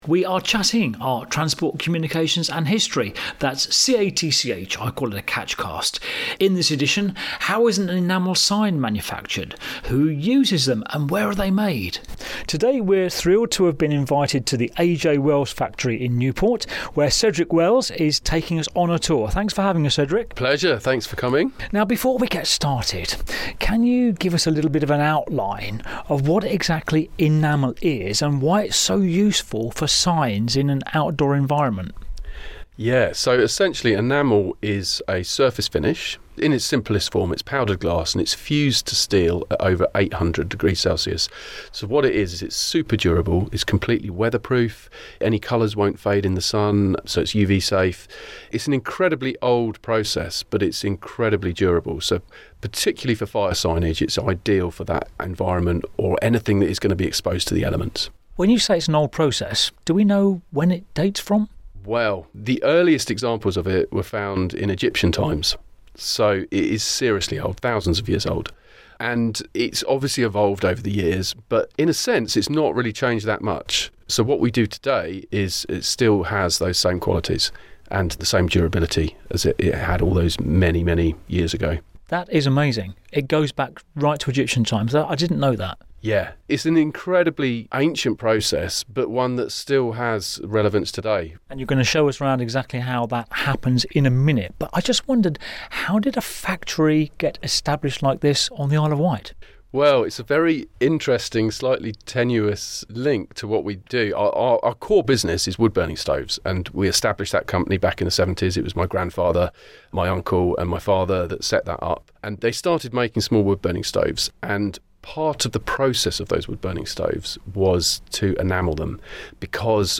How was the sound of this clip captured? takes us on a tour of this amazing facility,